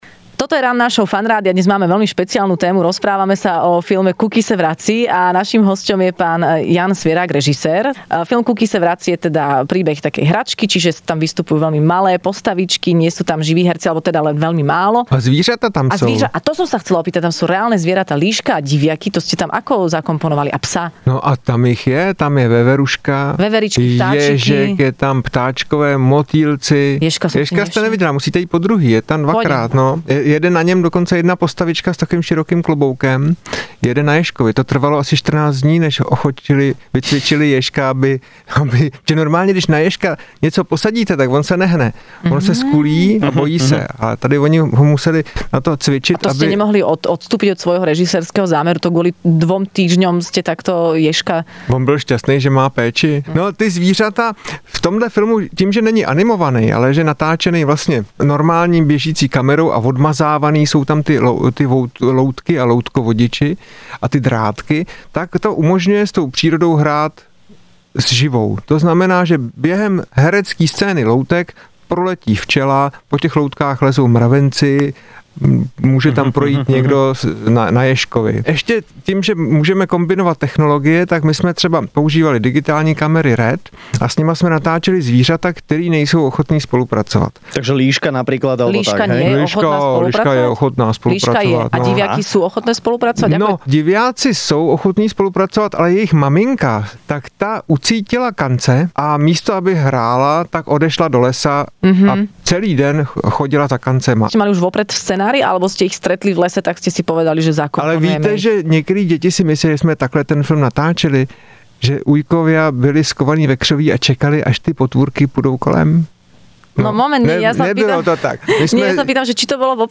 Hosťom v rannej šou Fun rádia bol režisér Jan Svěrák, ktorý porozprával o svojom novom filme Kuky se vrací...